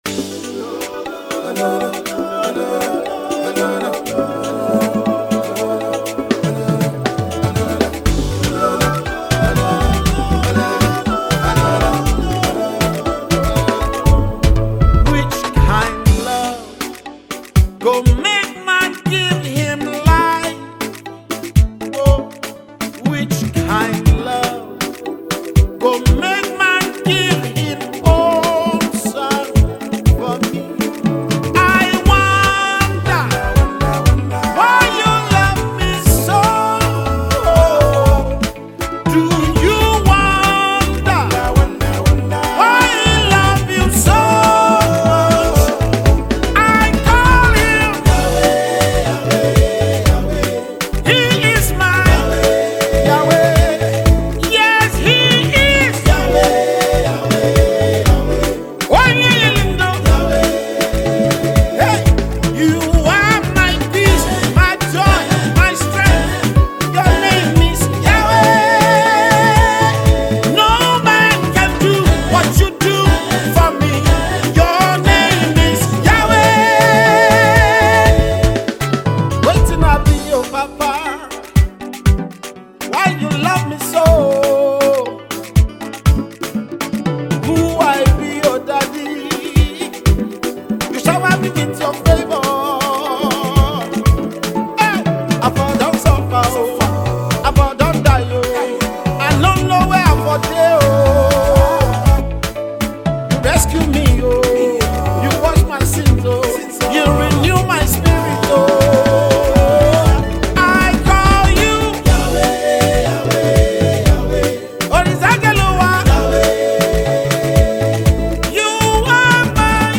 March 18, 2025 Publisher 01 Gospel 0